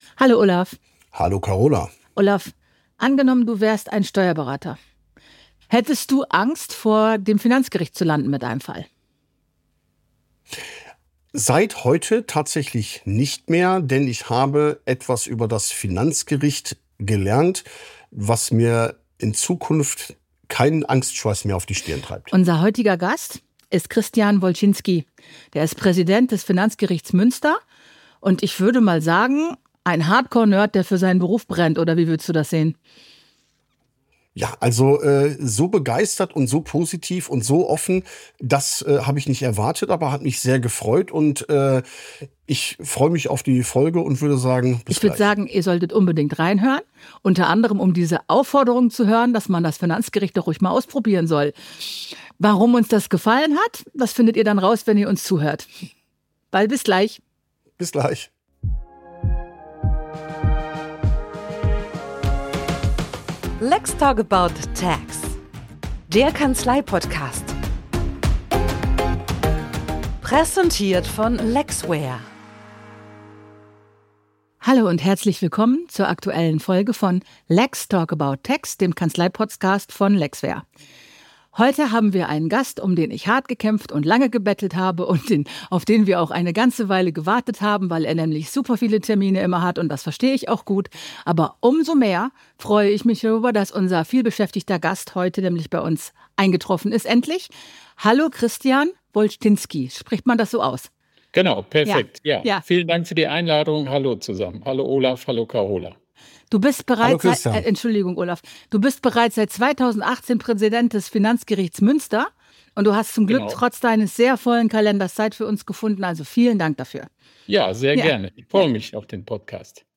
Zu Gast im lex'talk about tax Kanzlei-Podcast ist Christian Wolsztynski, der Präsident des Finanzgerichts Münster, der uns spannende Einblicke hinter die Kulissen gibt und erklärt, warum keine Steuerberaterin und kein Steuerberater den Gang vors Gericht scheuen sollte - immerhin 50% aller Fälle werd...